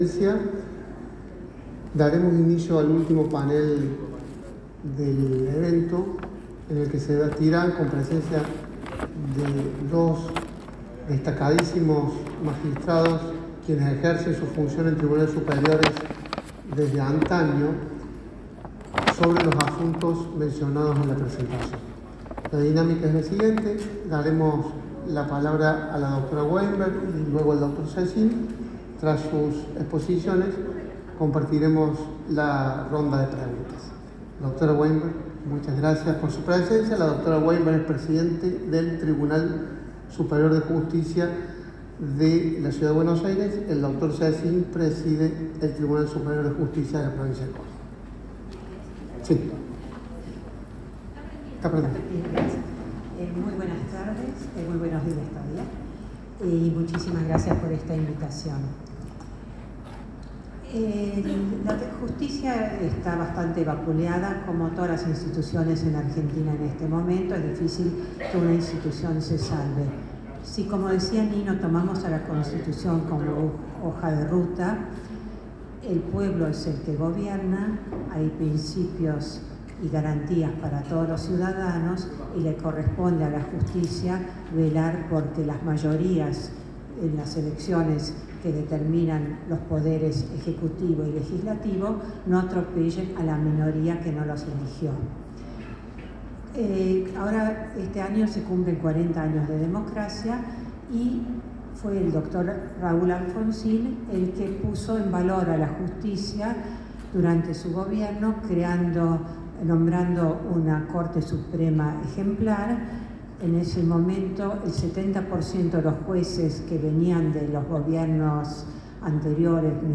AMCHAM ENTREVISTA A JUEZ INES WEINBERG PTE TSJ CABA Y JUEZ DOMINGO SESIN PTE TSJ CORDOBA.mp3